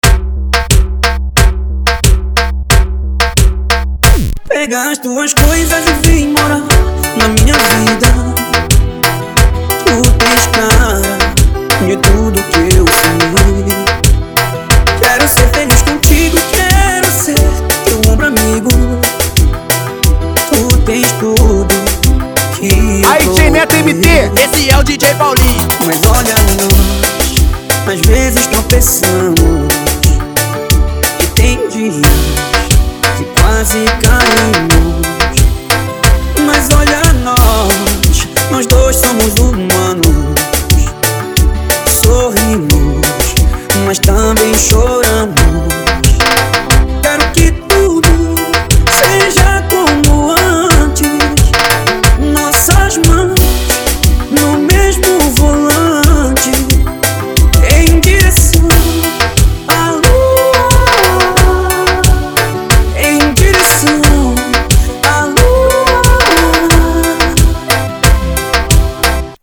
Tecno Melody